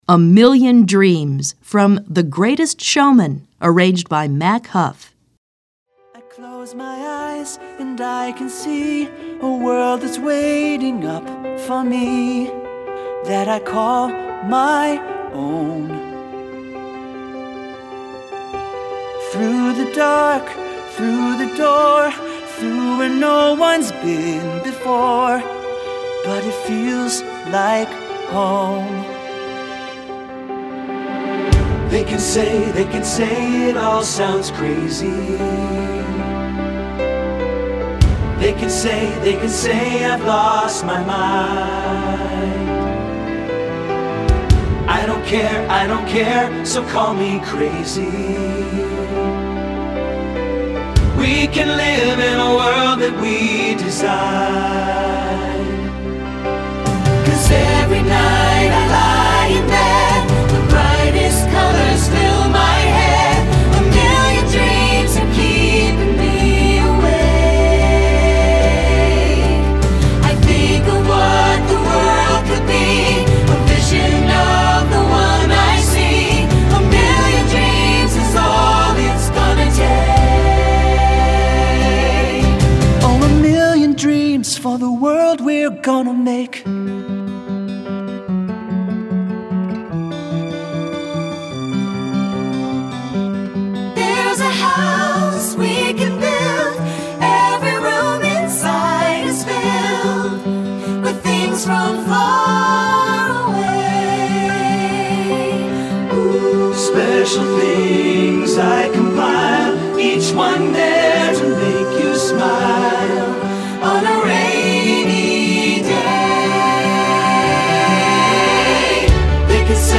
Choral Graduation/Inspirational Movie/TV/Broadway
Voicing
SATB